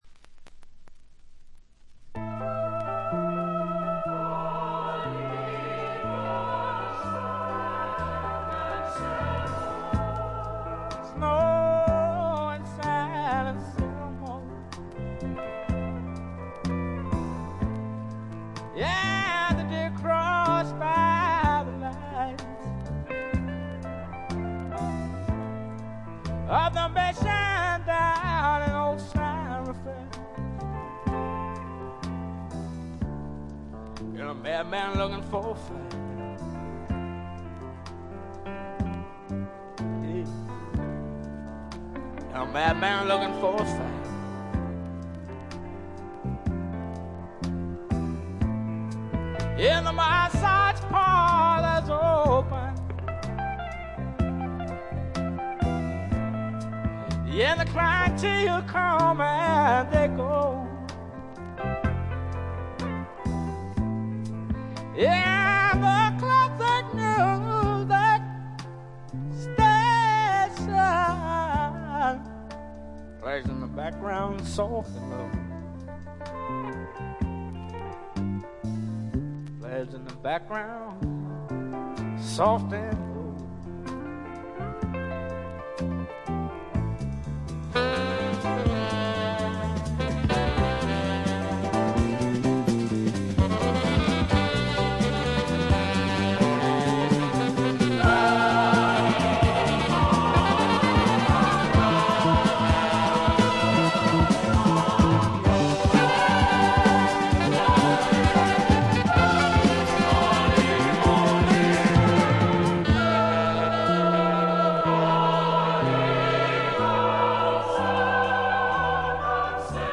acoustic guitar, vocals